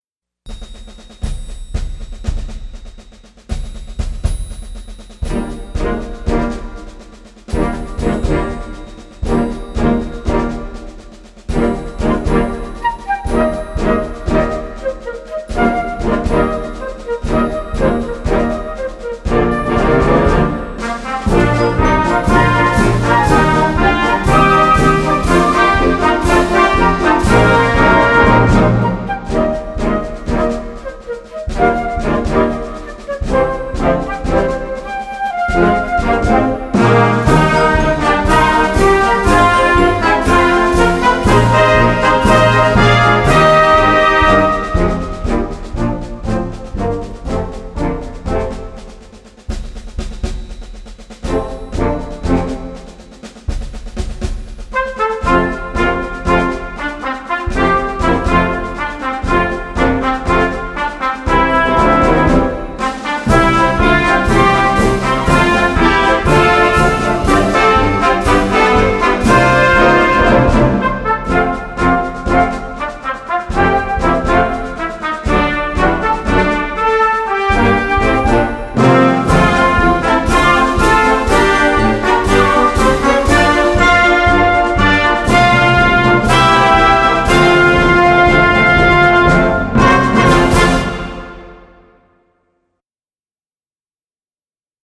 Blasmusik für Jugendkapelle Schwierigkeit
Blasorchester Tonprobe